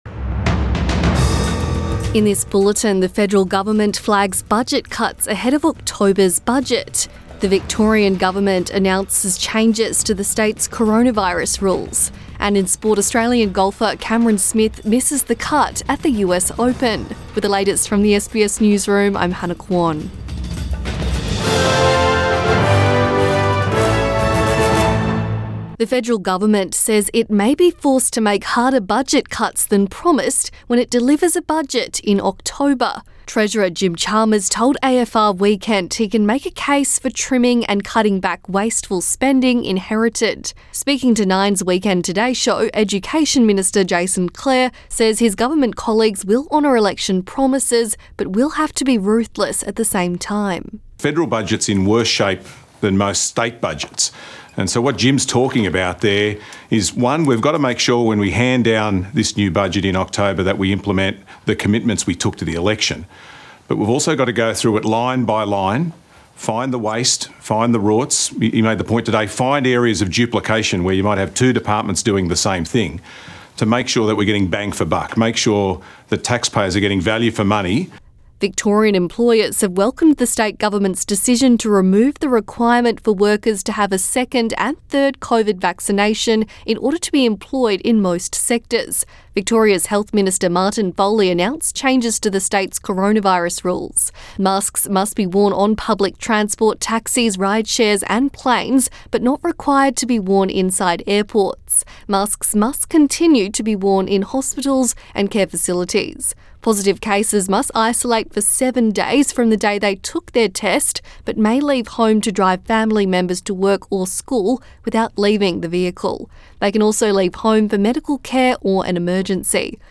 Midday bulletin 18 June 2022